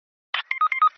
Death sound (Fortnite)
Trending under: Sound, Effect, Meme Sound.